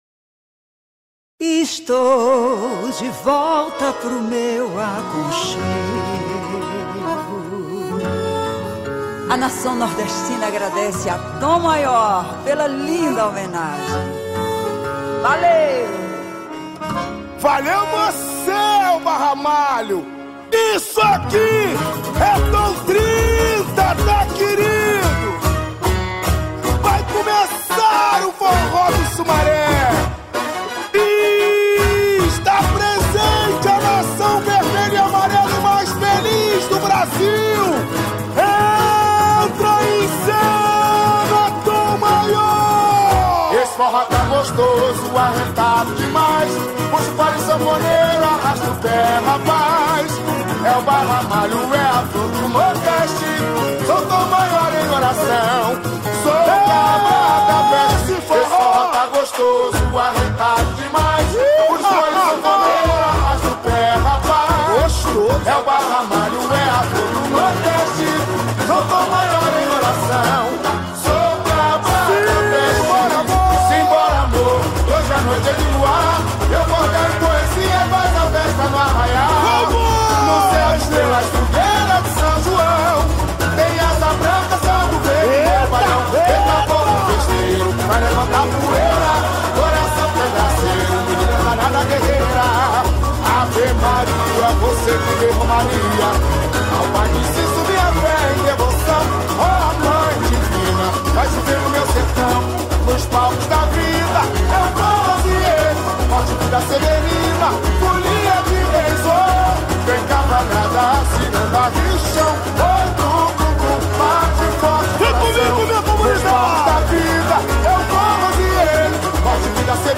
Interprete: